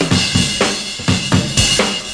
112CYMB05.wav